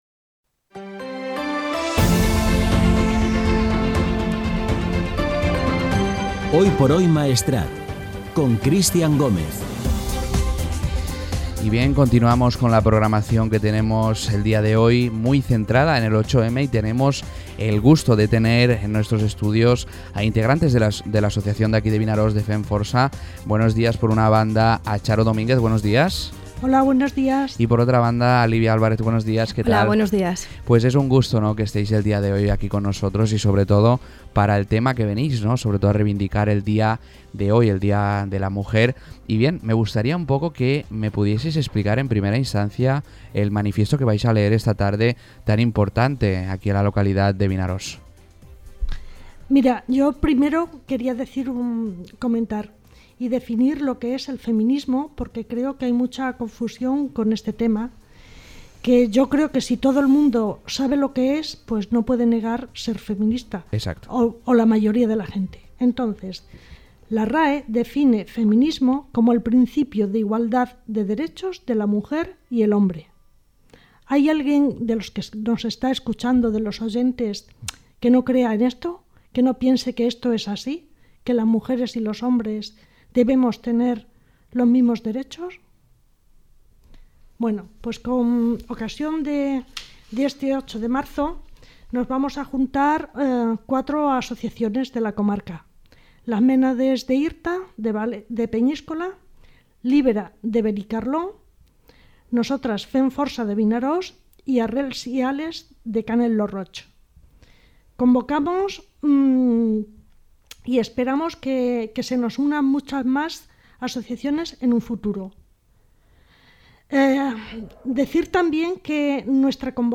Entrevistes en motiu del 8 de març: Femme Força Vinaròs